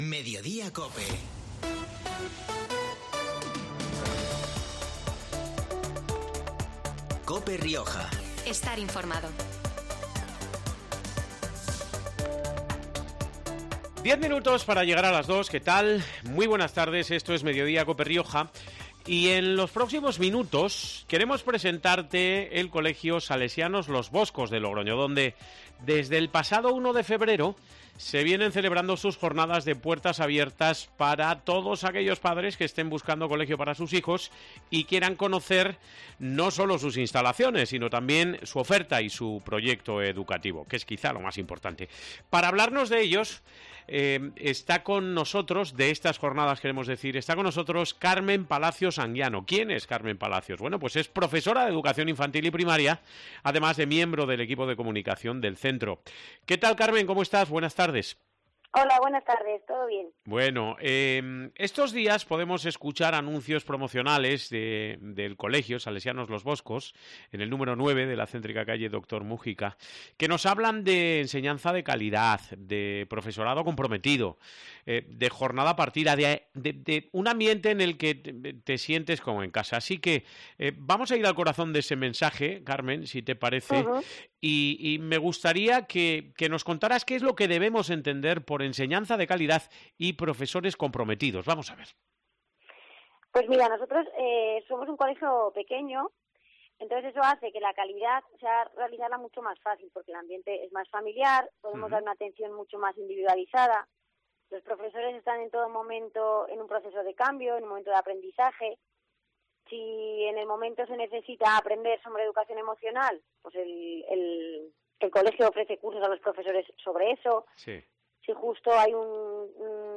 Para hablarnos de ello nos ha atendido por teléfono